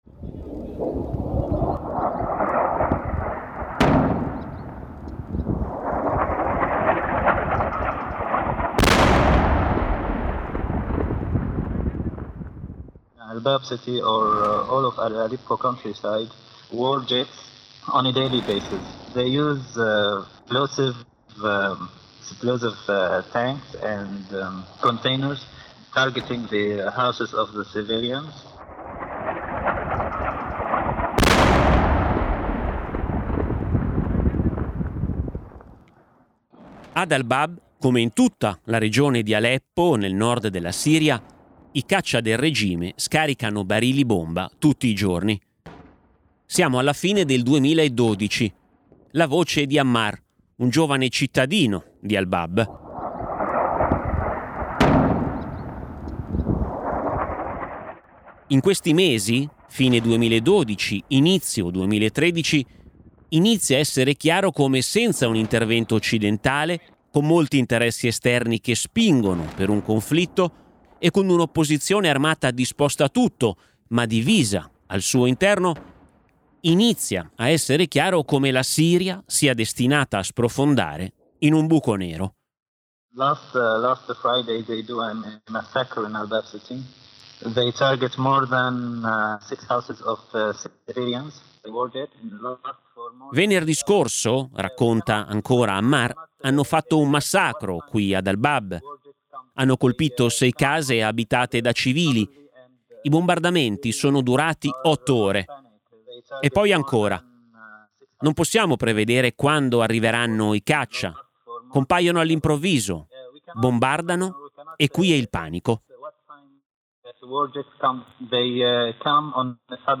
Con mezzo milione di morti, oltre dodici milioni di profughi e un paese devastato, il podcast indaga le origini del conflitto, la situazione pre-2011, l'evoluzione della rivoluzione contro Bashar al-Assad e il ruolo delle potenze mondiali. Attraverso le voci di cittadini siriani, dentro e fuori il Paese, il podcast cerca di rispondere a queste e altre domande sul conflitto in corso.